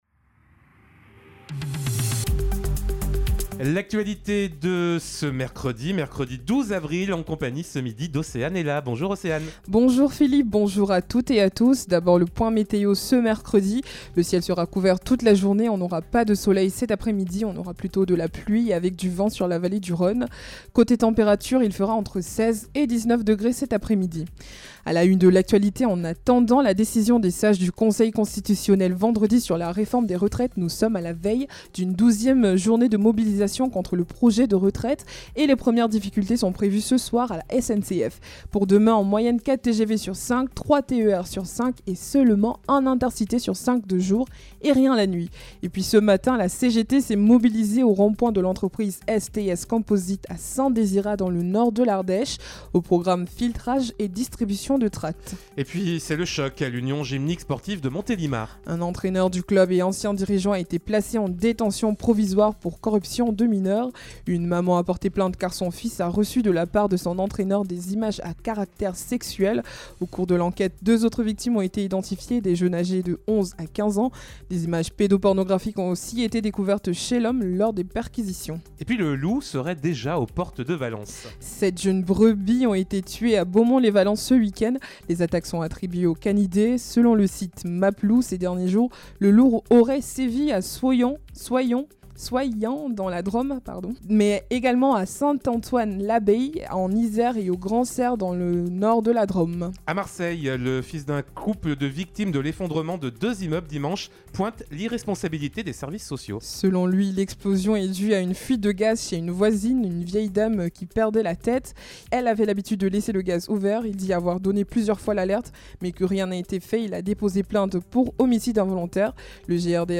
Mercredi 12 avril : Le journal de 12h